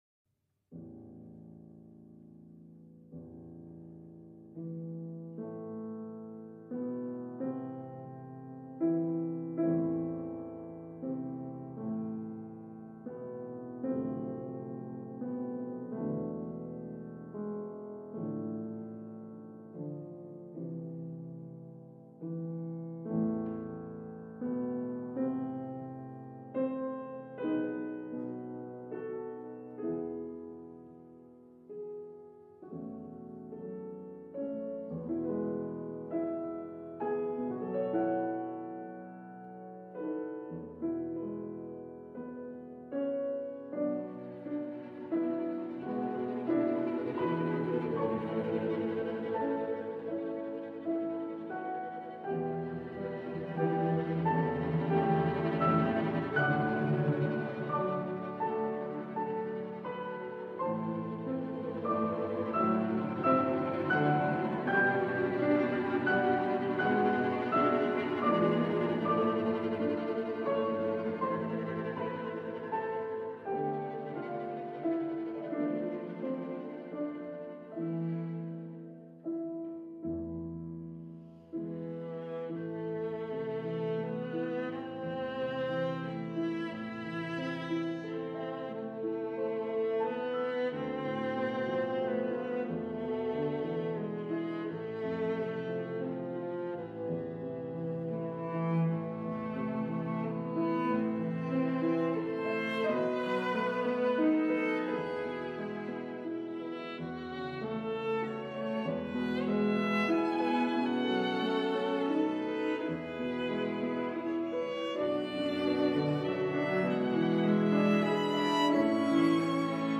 The middle movement, Lento, begins in somber fashion before the piano plays a somewhat sad folk song over the soft tremolos in the strings. The entrance of the strings afterwards further adds an aura of melancholy.